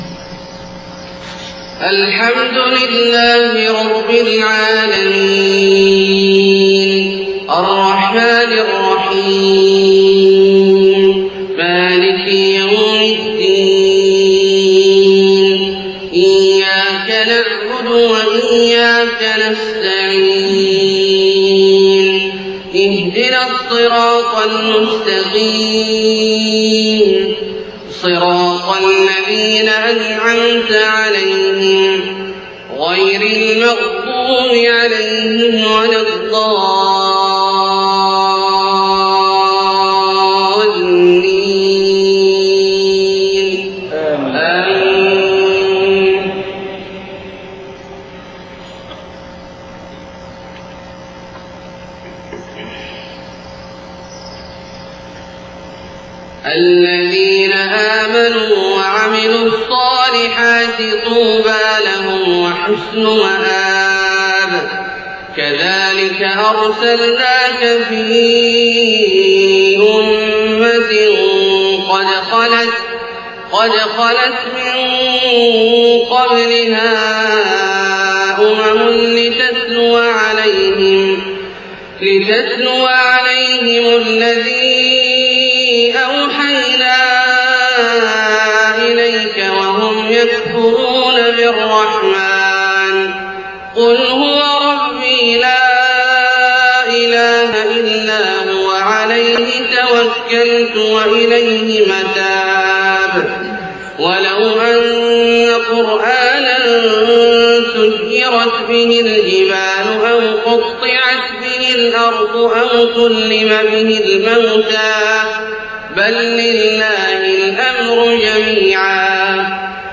صلاة الفجر 14صفر 1430هـ من سورة الرعد 29-43 > 1430 🕋 > الفروض - تلاوات الحرمين